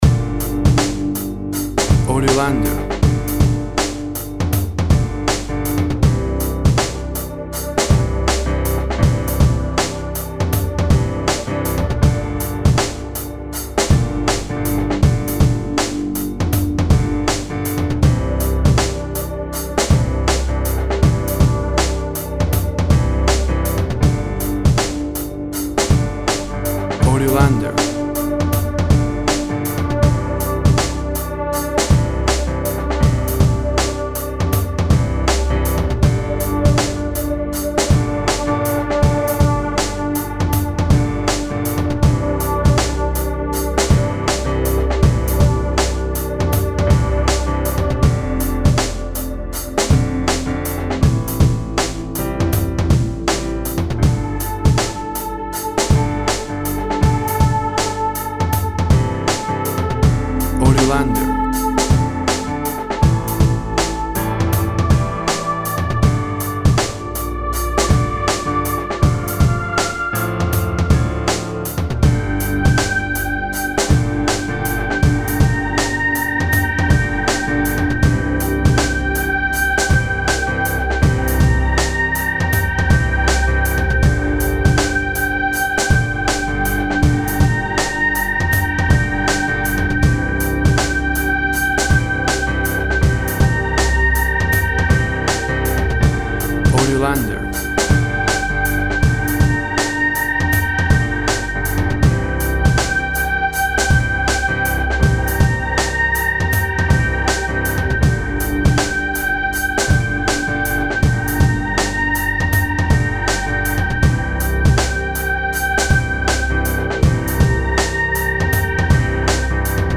Beat very intense for various times of stress.
Tempo (BPM) 85